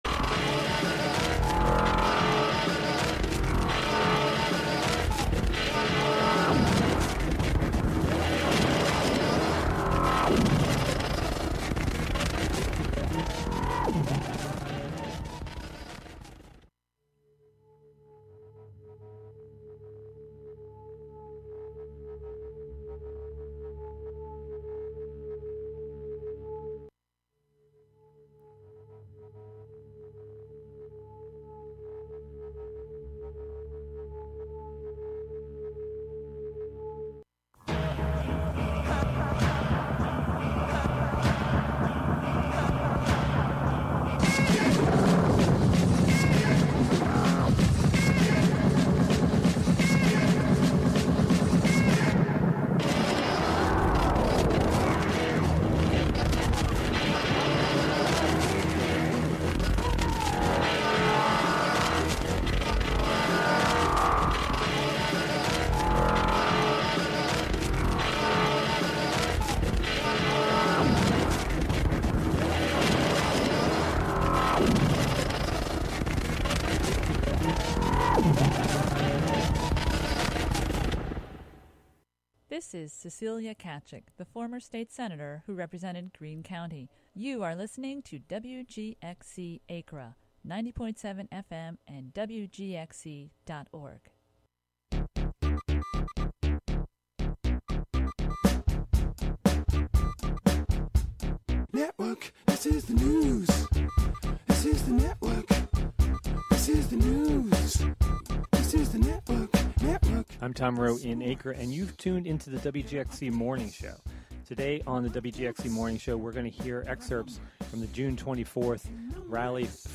10am This excerpt from the June 24, Albany, NY, protest...